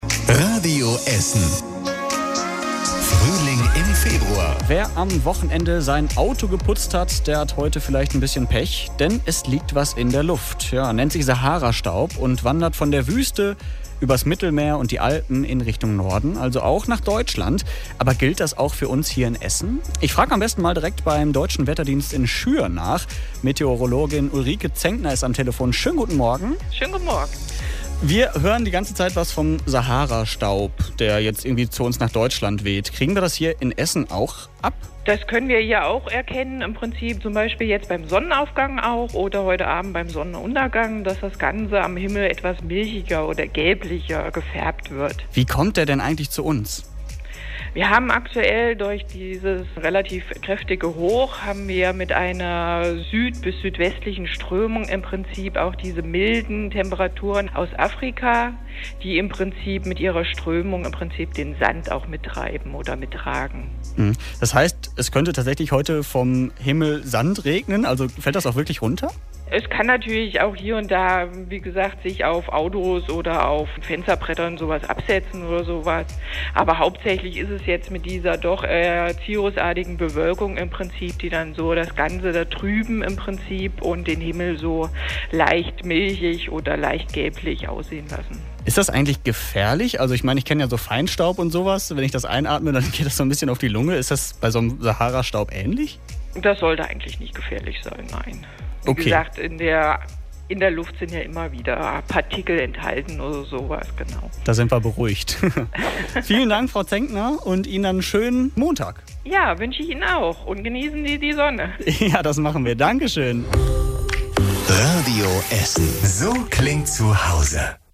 radio_essen_telefon-interview_zum_sahara-sand_mit_dem_deutschen_wetterdienst.mp3